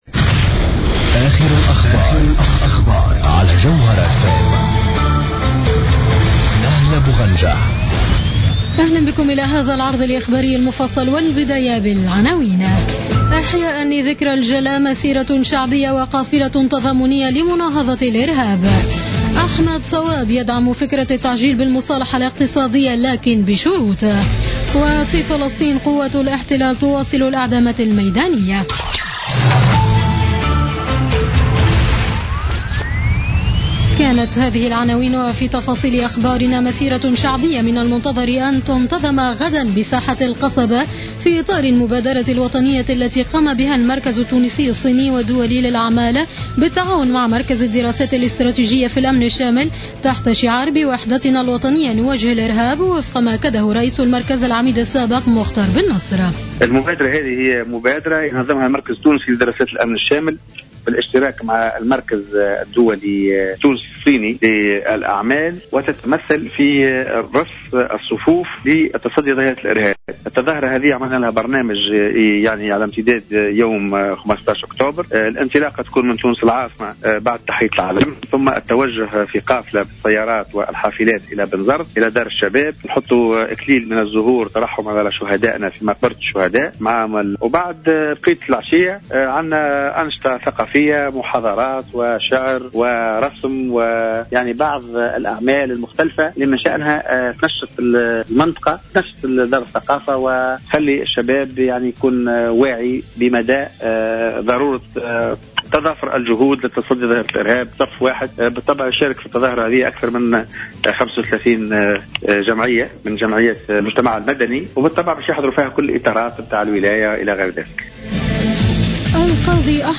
نشرة أخبار السابعة مساء ليوم الأربعاء 14 أكتوبر 2015